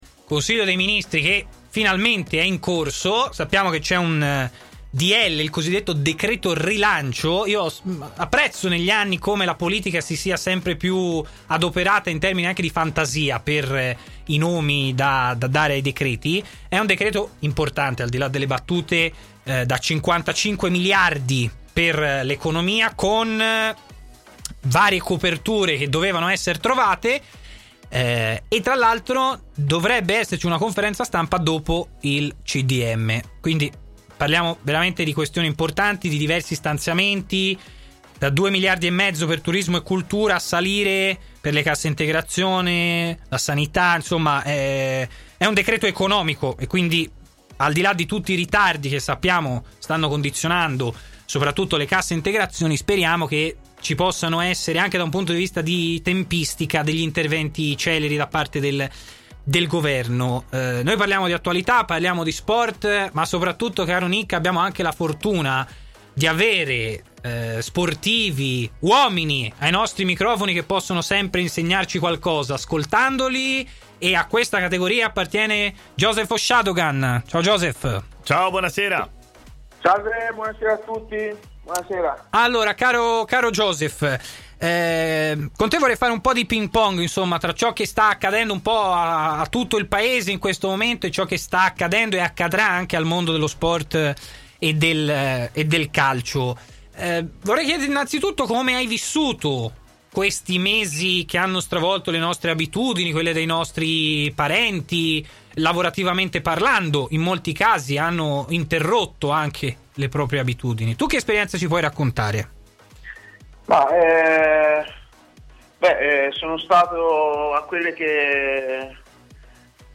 intervenuto ai microfoni di TMW Radio, nel corso della trasmissione Stadio Aperto